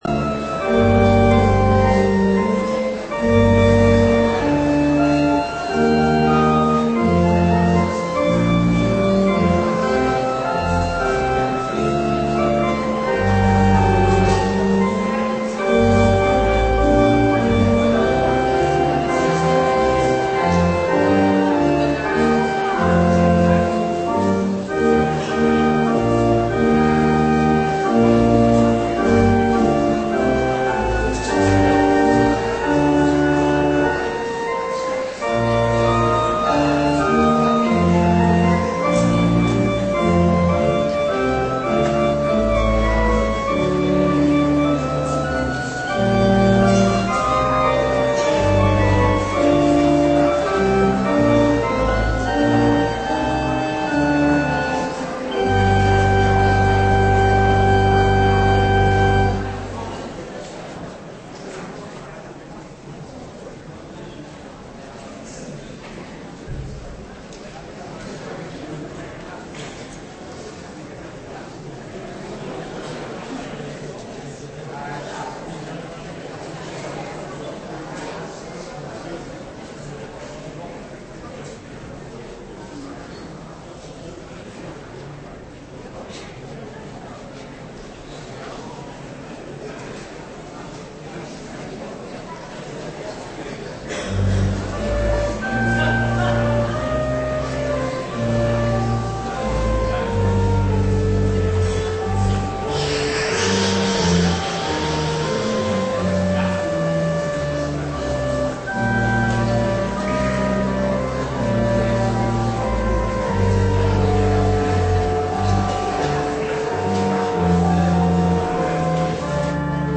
Preek over Jesaja 11:1 op zondagmorgen 16 december 2018 (3e adventszondag) - Pauluskerk Gouda